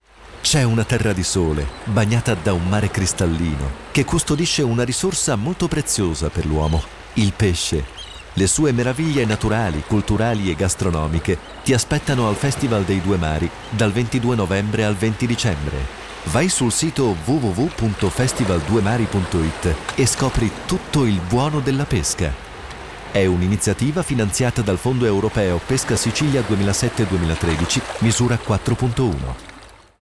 Spot
radiocomunicato_festival_dei_due_mari_1-2.mp3